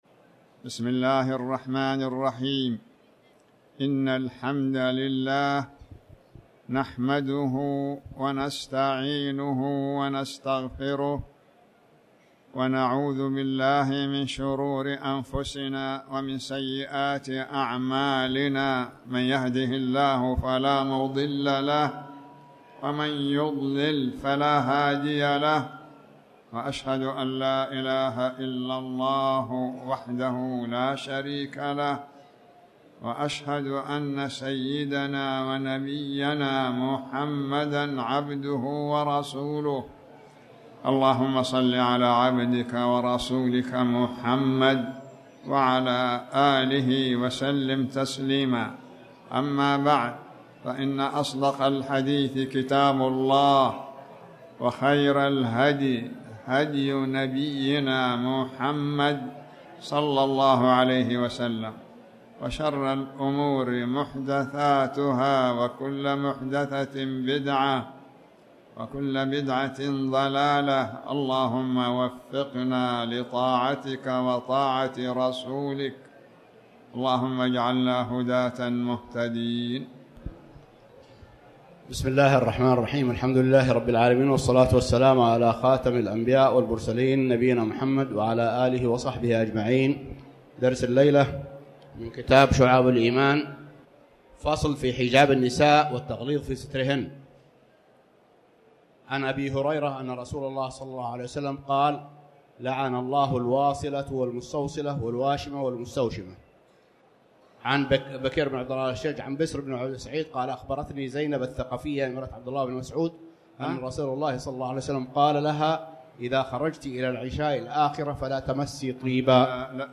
تاريخ النشر ١٧ رجب ١٤٣٩ هـ المكان: المسجد الحرام الشيخ